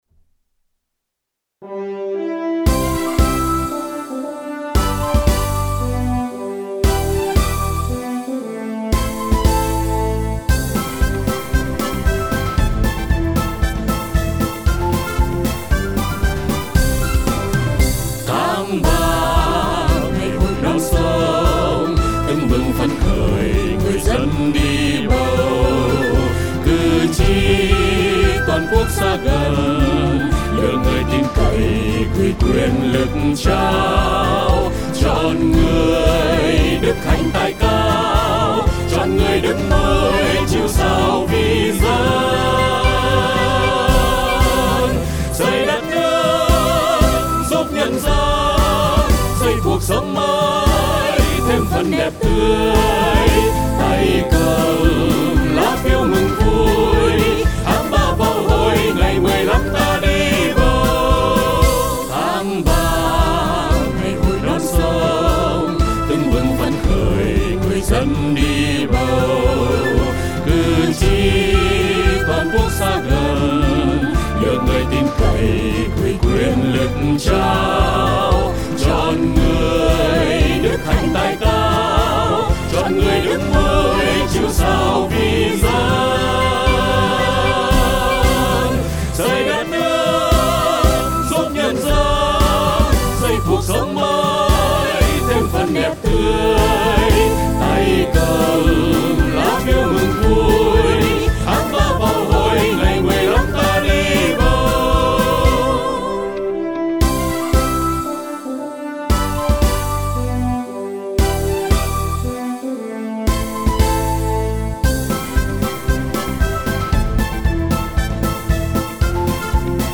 tỉnh Tuyên Quang đã sáng tác bài hát Ngày Hội Non Sông để góp phần vào cuộc vận động trong thời gian tới.